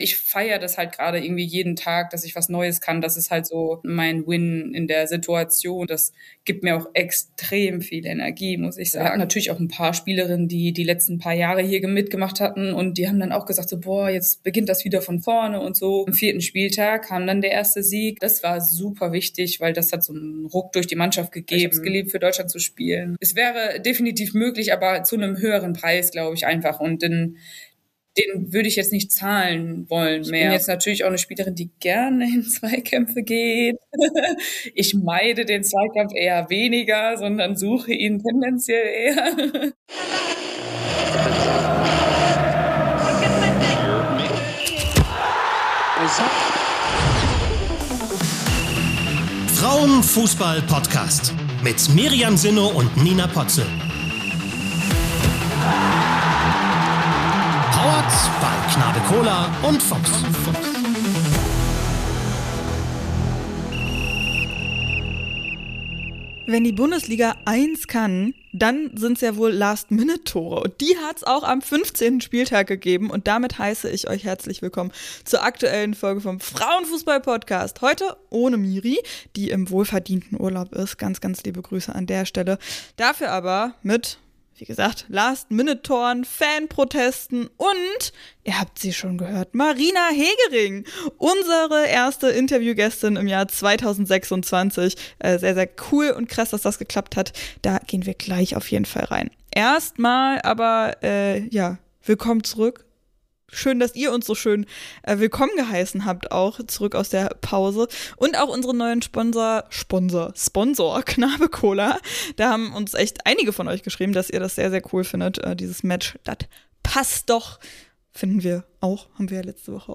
Beschreibung vor 2 Monaten Unsere erste Interviewfolge 2026 und direkt mit so einer Legende!
Mal wieder, es ist ein leidiges Thema für sie, und trotzdem erzählt sie recht entspannt, auch von Druck & Erwartungen an die Saison. Außerdem: Spieltag 15 liefert zwei Spielabsagen, aber auch Last-Minute-Tore und -Strafstöße und Fanproteste.